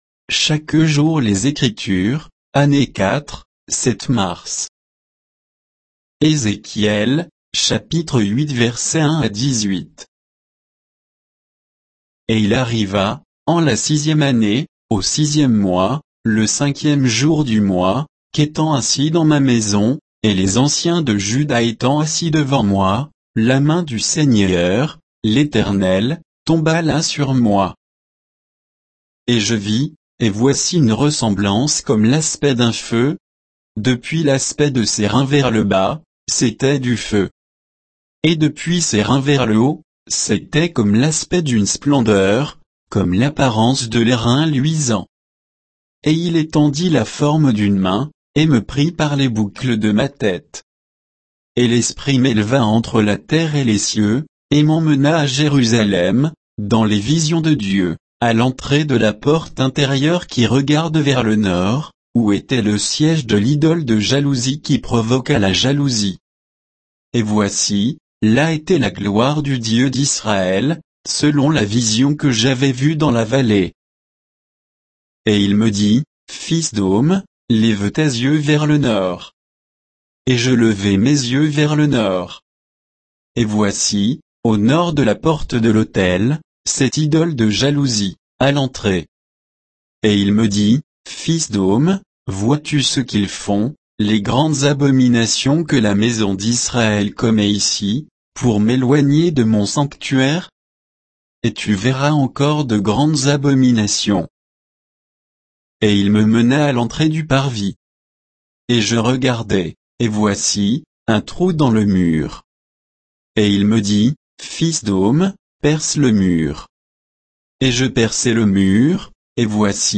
Méditation quoditienne de Chaque jour les Écritures sur Ézéchiel 8, 1 à 18,